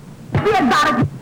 Guest EVPs